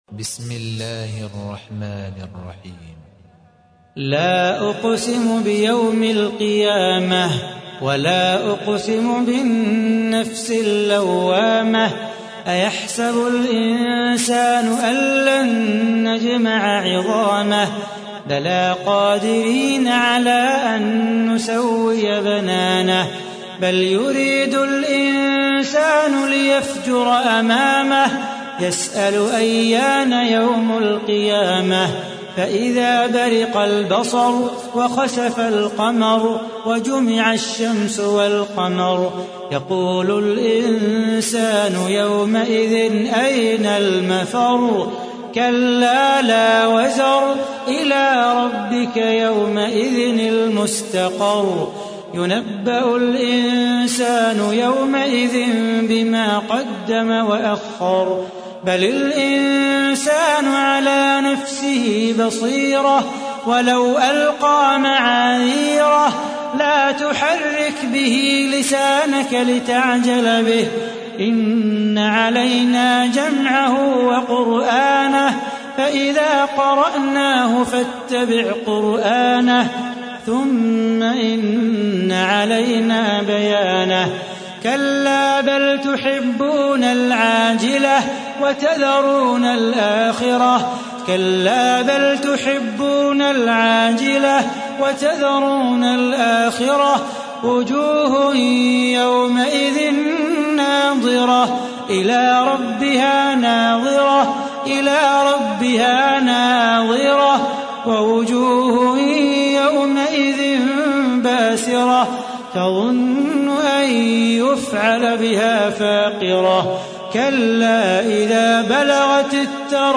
تحميل : 75. سورة القيامة / القارئ صلاح بو خاطر / القرآن الكريم / موقع يا حسين